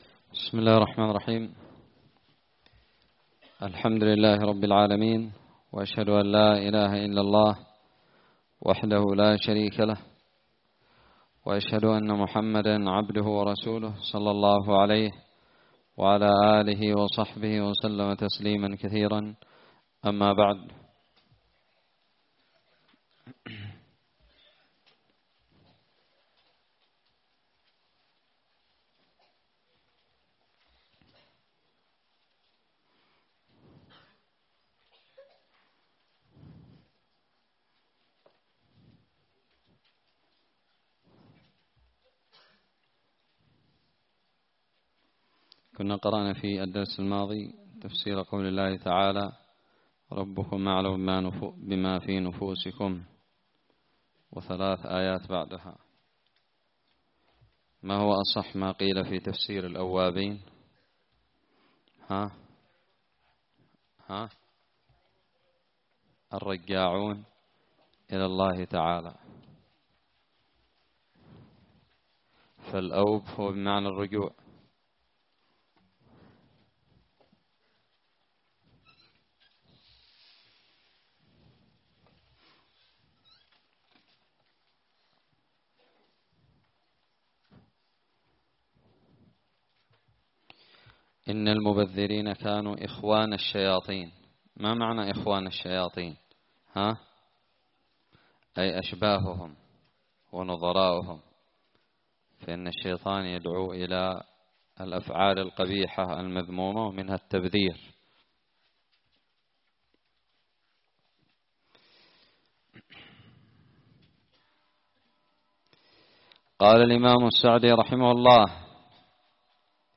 الدرس الثامن من تفسير سورة الإسراء
ألقيت بدار الحديث السلفية للعلوم الشرعية بالضالع